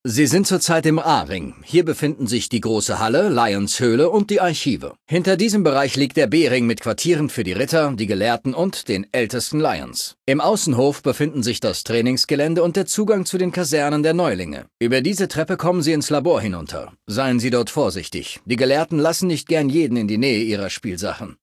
Datei:Maleadult01default dialogueci citknightdirect 00026c40.ogg
Fallout 3: Audiodialoge